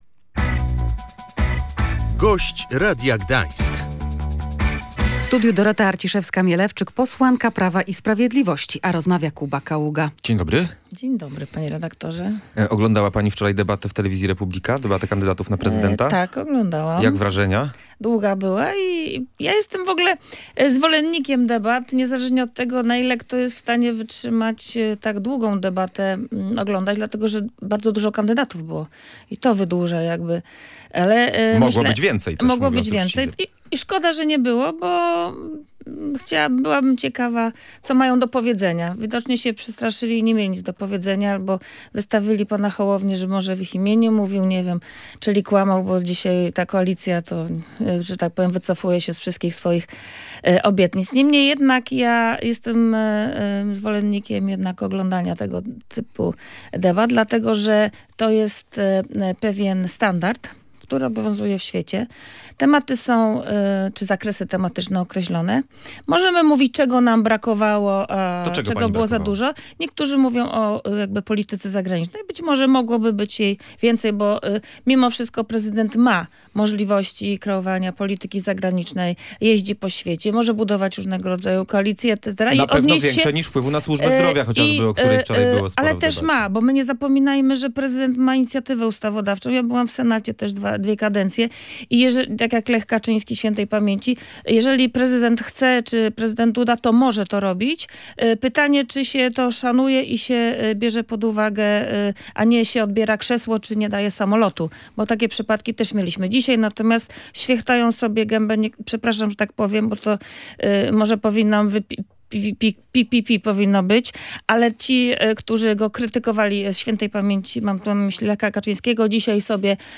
Polityka zagraniczna powinna być jednym z głównych tematów debat kandydatów na prezydenta Polski – uważa posłanka Prawa i Sprawiedliwości Dorota Arciszewska-Mielewczyk. Podkreślała w Radiu Gdańsk, że prezydent ma duży wpływ na kształtowanie polityki zagranicznej.